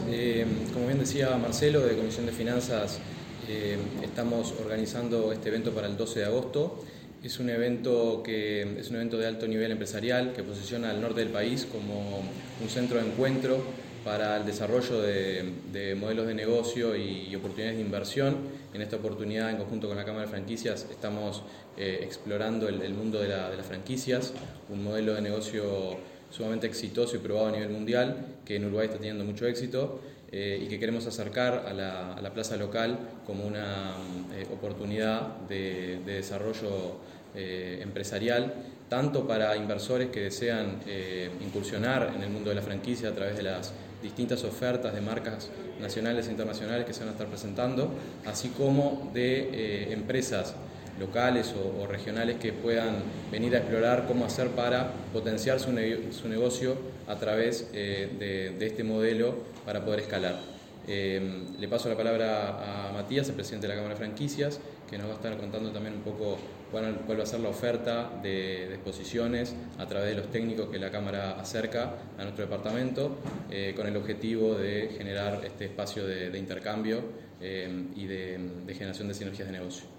Este martes 29 de junio se realizó la conferencia de prensa de lanzamiento del Foro de Negocios Salto 2025, que se llevará a cabo el próximo 12 de agosto en el Centro Comercial e Industrial.